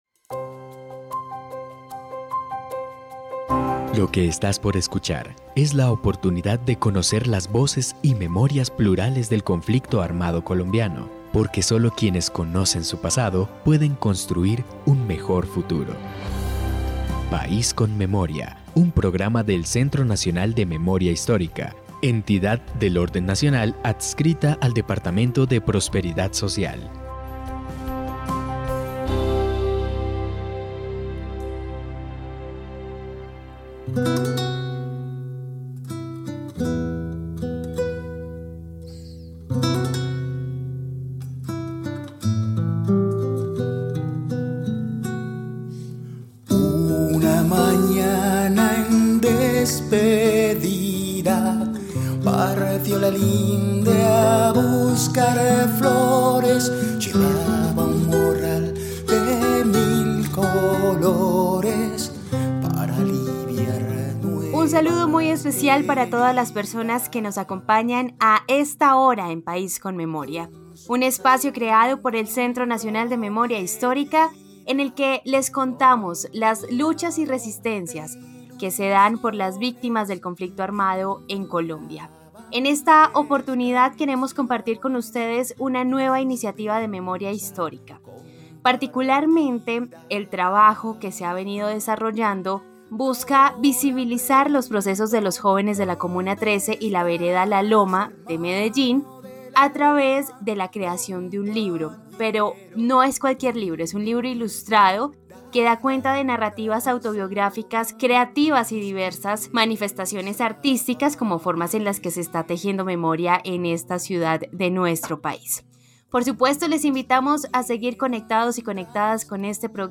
Descripción (dcterms:description) Capítulo número 25 de la cuarta temporada de la serie radial "País con Memoria". Proceso de construcción de memoria que se realizó para recopilar, reconstruir y difundir las memorias plurales y diversas de colectivos de la comuna 13 y la vereda La Loma.